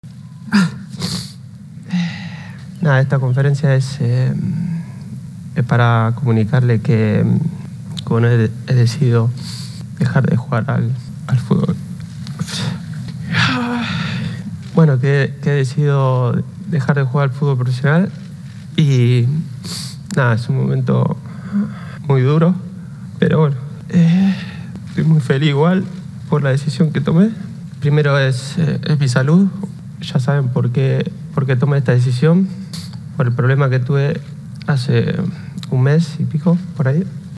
(Sergio Agüero en rueda de prensa)